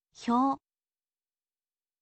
ออกเสียง: hyo, เฮียว, ฮิโยะ
เสียงนี้เป็น “ฮิโยะ” เมื่อเสียงเขียนเป็นภาษาไทย แต่มันใกล้กับ “เฮียว” ตั้งใจฟังเสียงและเลียนแบบกันเถอะ